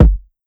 Killed Orchestra Kick.wav